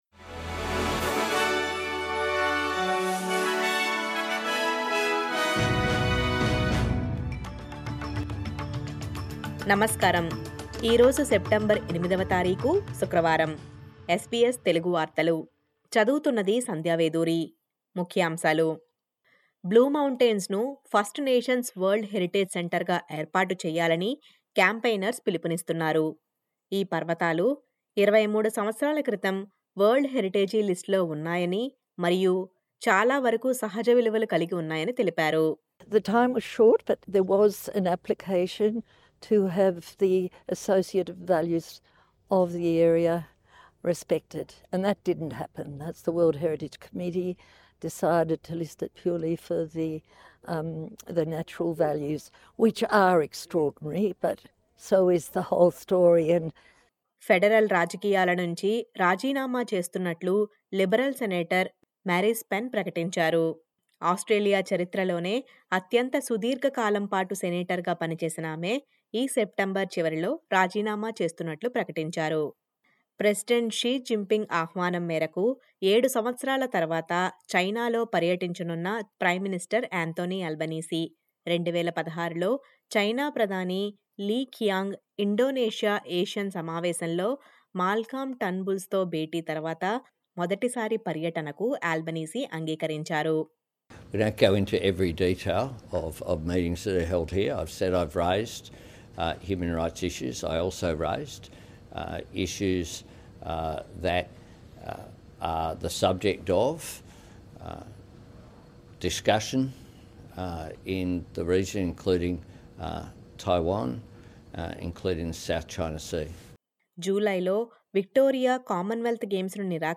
SBS Telugu వార్తలు.